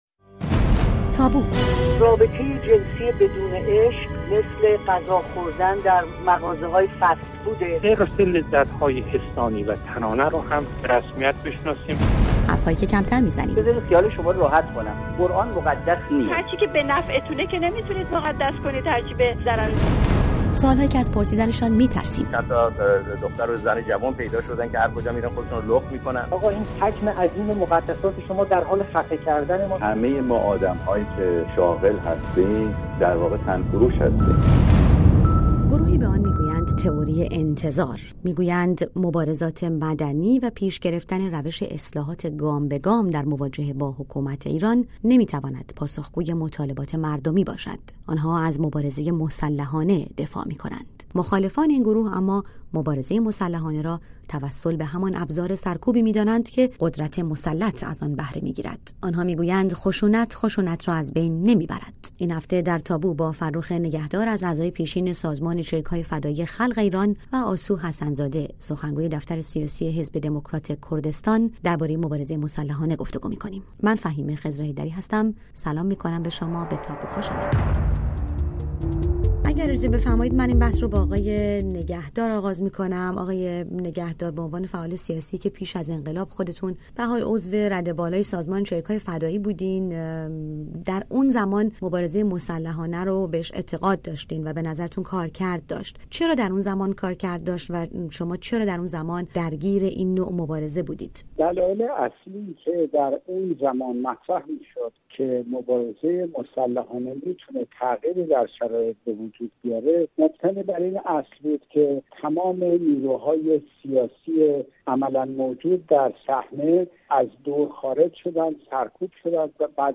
بر سر همین موضوع به بحث نشسته‌اند